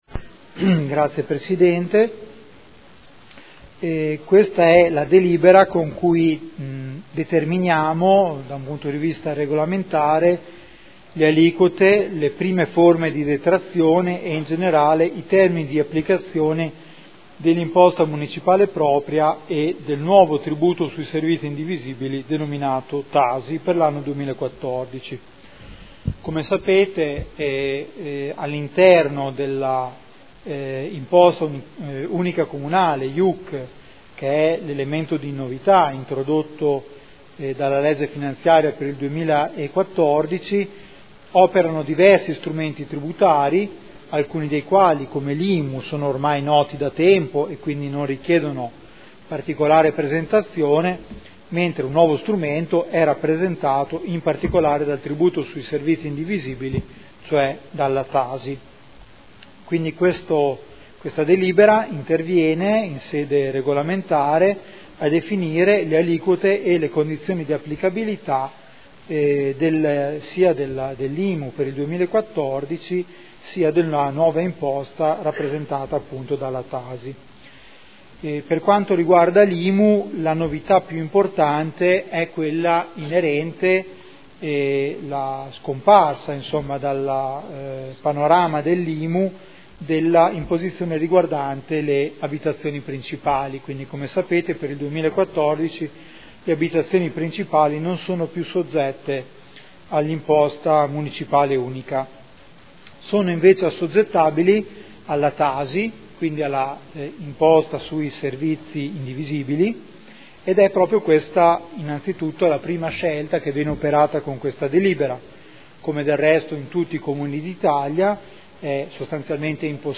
Giuseppe Boschini — Sito Audio Consiglio Comunale
Seduta del 13 marzo. Proposta di deliberazione: Imposta Unica Comunale (IUC) – Determinazione regolamentare delle aliquote, detrazioni e dei termini di applicazione dell’Imposta Municipale propria (IMU) e del tributo sui servizi indivisibili (TASI) per l’anno 2014